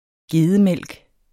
Udtale [ ˈgeːðə- ]